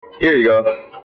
m_go.mp3